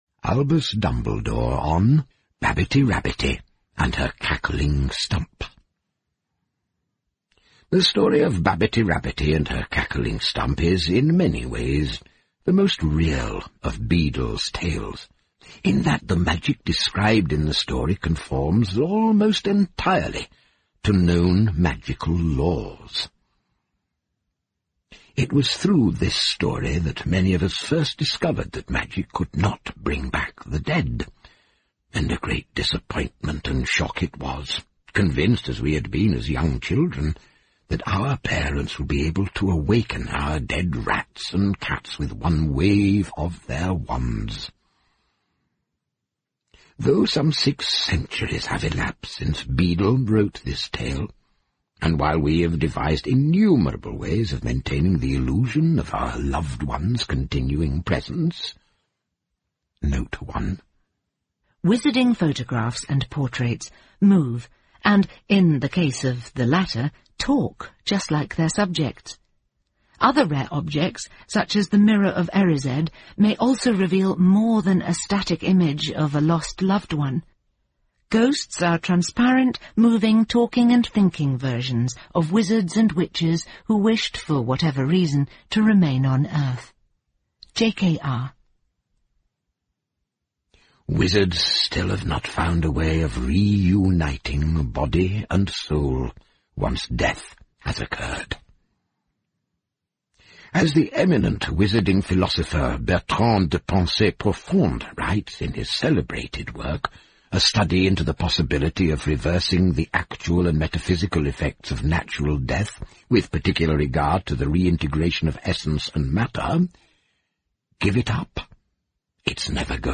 在线英语听力室诗翁彼豆故事集 第21期:兔子巴比蒂和她的呱呱树桩(5)的听力文件下载,《诗翁彼豆故事集》栏目是著名的英语有声读物，其作者J.K罗琳，因《哈利·波特》而闻名世界。